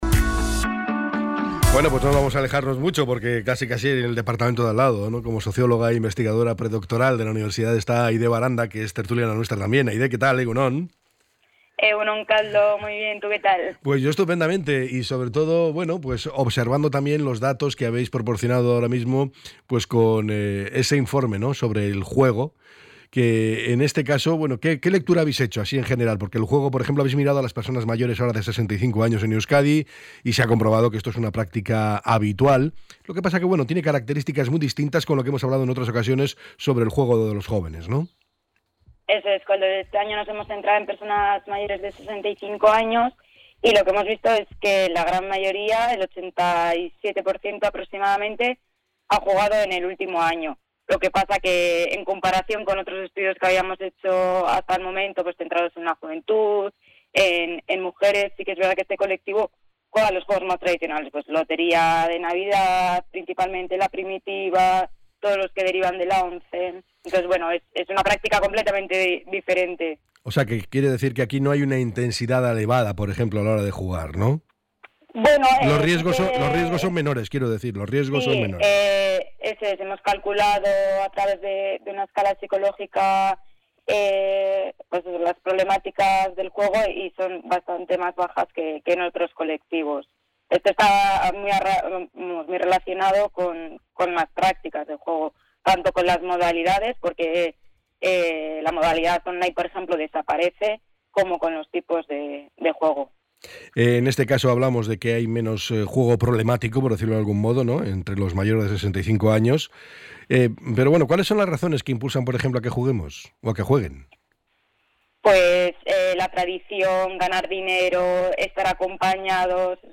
El programa EgunOn Bizkaia ha contado con la participación de la socióloga